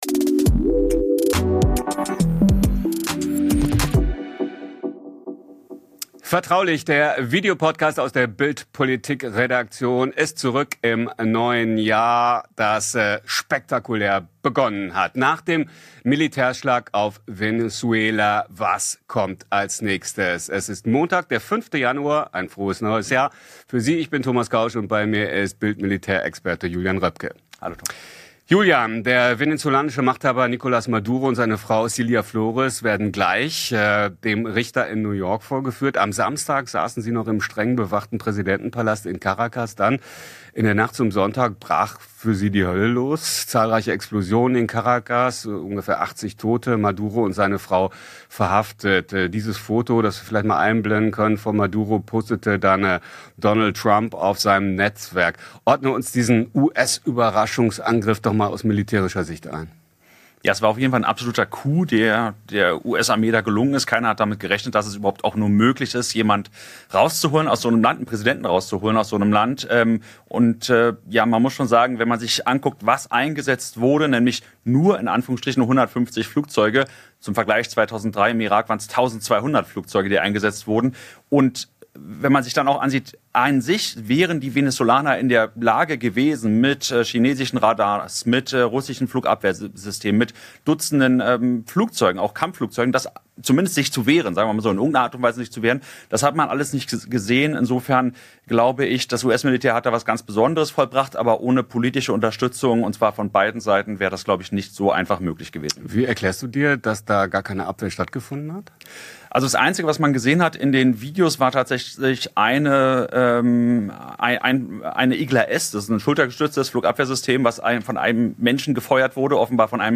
Nach dem Militärschlag: Paul Ronzheimer live aus Südamerika